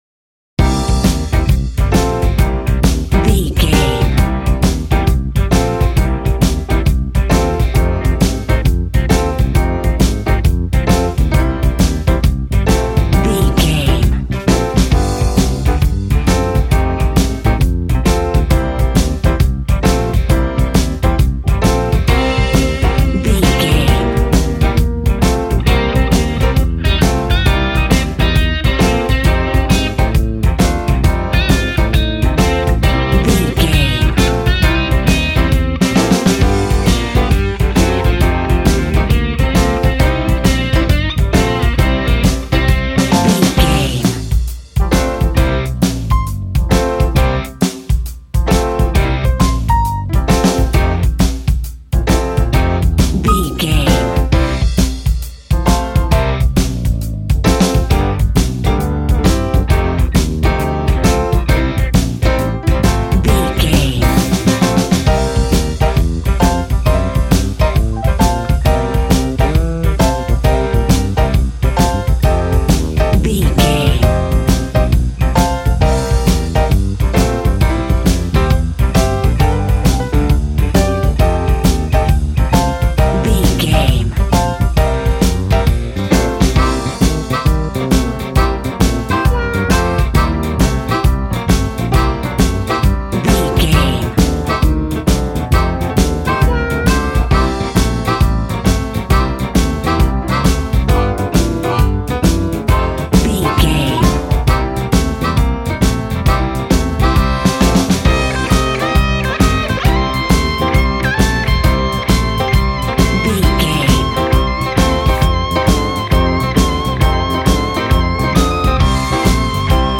Ionian/Major
sad
mournful
bass guitar
electric guitar
electric organ
drums